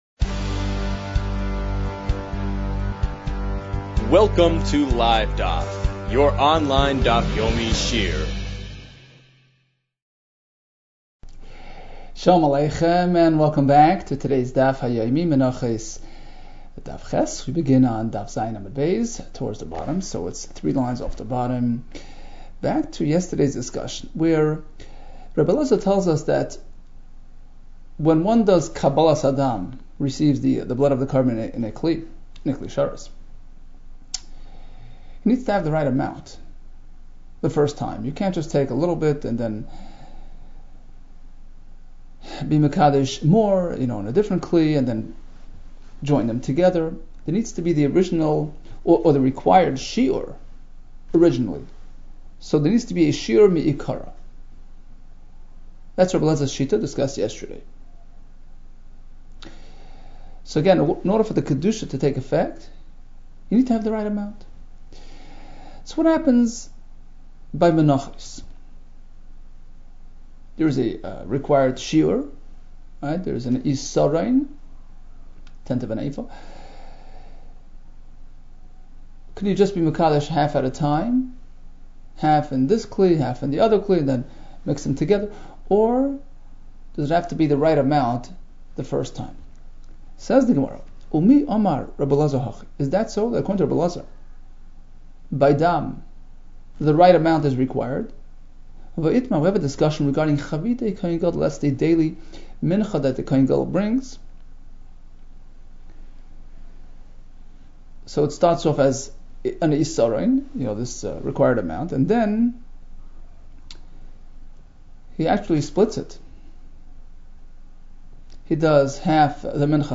Menachos 8 - מנחות ח | Daf Yomi Online Shiur | Livedaf